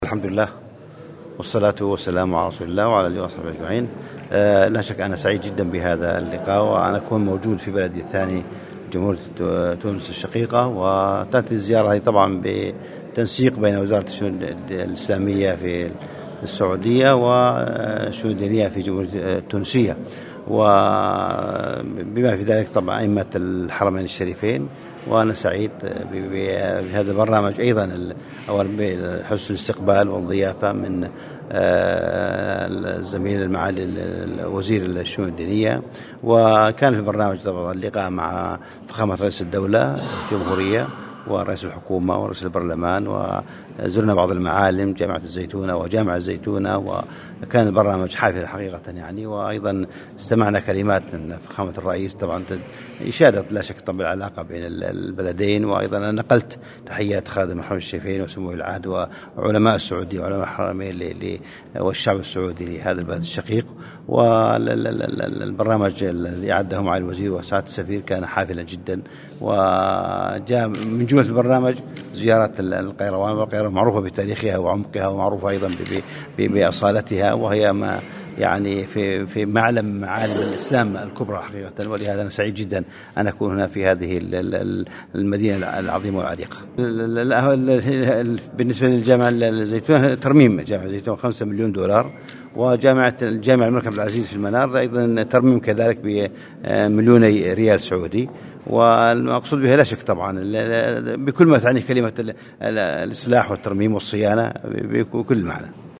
وأكد في تصريح على هامش زيارة اداها اليوم الى ولاية القيروان أهمية عاصمة الغالبة من ناحية عمقها التاريخي والديني.